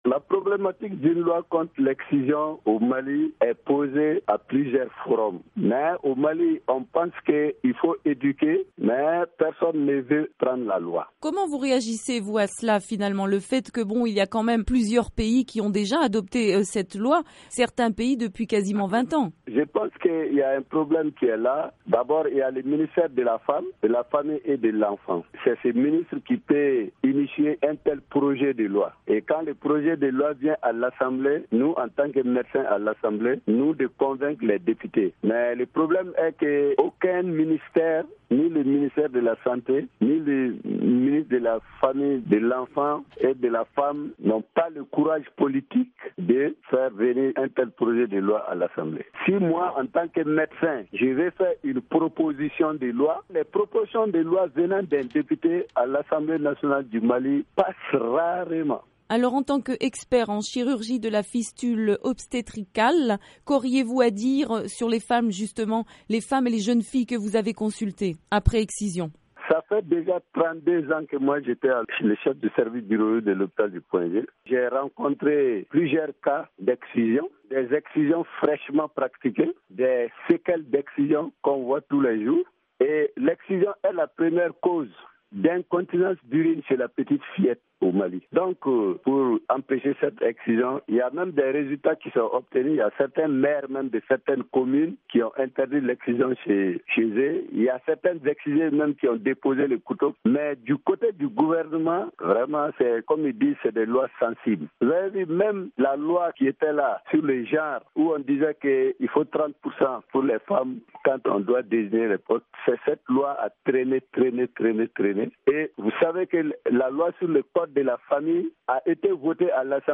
MGF 2017- Dr Kalilou Ouattara, député malien